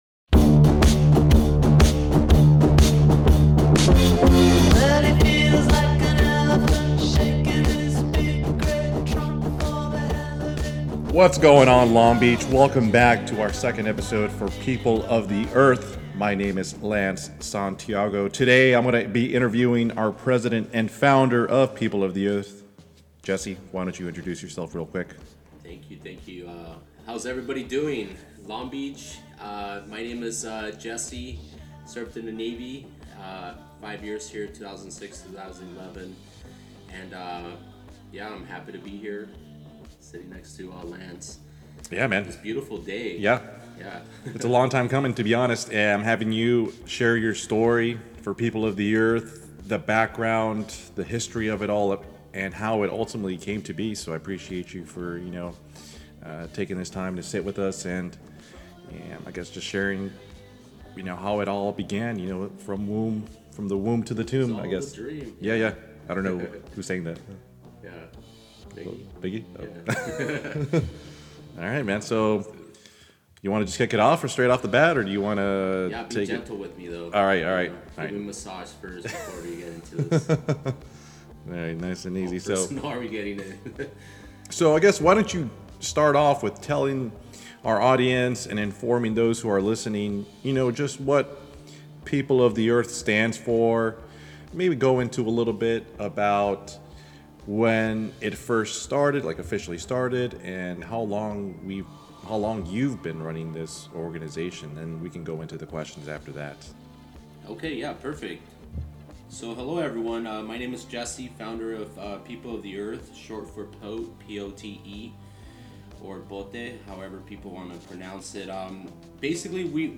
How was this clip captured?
This episode aired live on CityHeART Radio on Friday June 23, 2023 at 2pm.